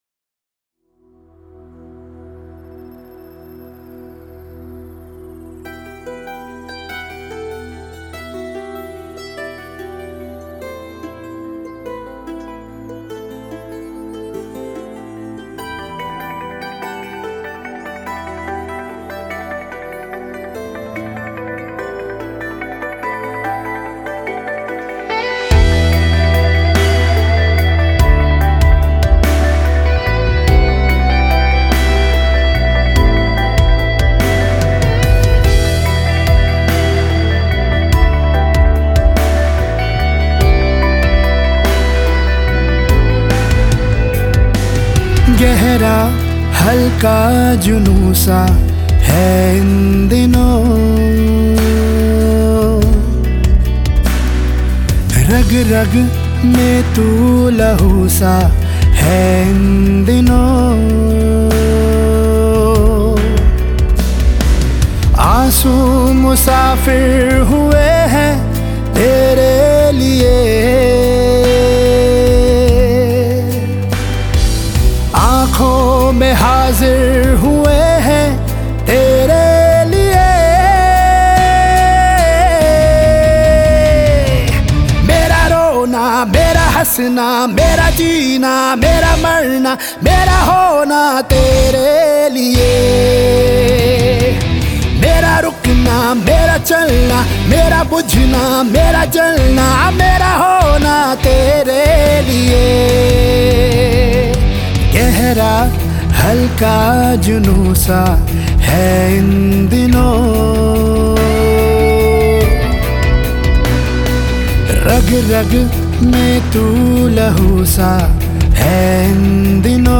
Hindi Movie